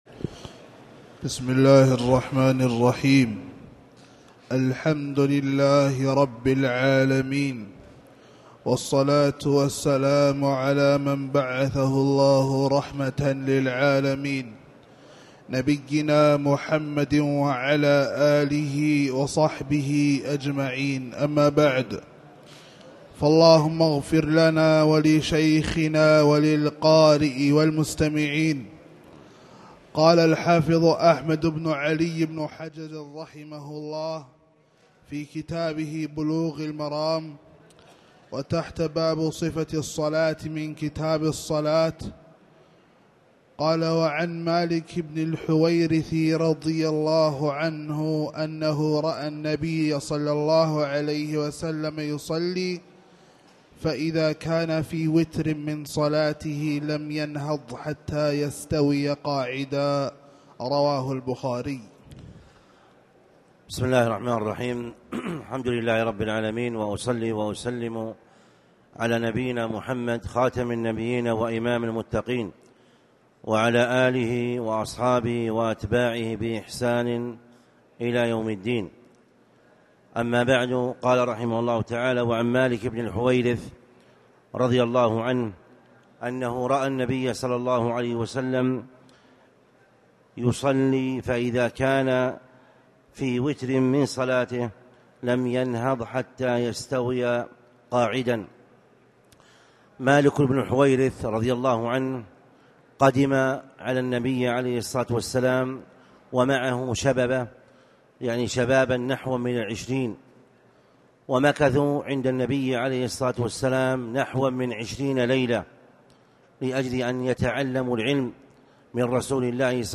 تاريخ النشر ٢ شعبان ١٤٣٨ هـ المكان: المسجد الحرام الشيخ